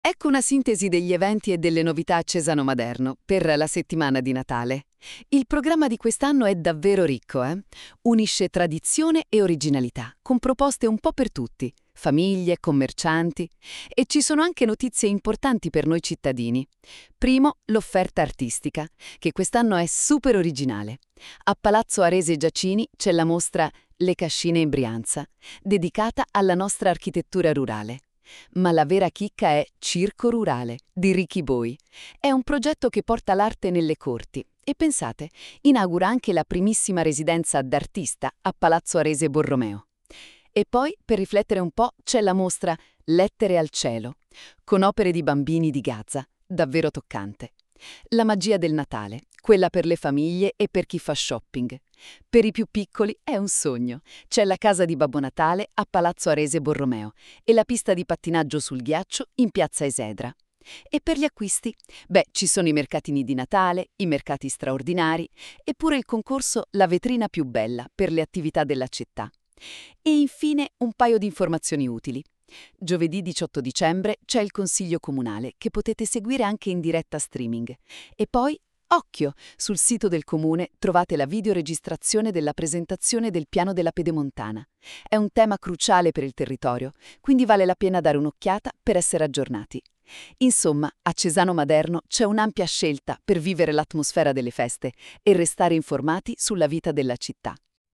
Il podcast è stato realizzato con l’ausilio dell’IA, potrebbe contenere parziali errori nelle pronunce o in alcune definizioni.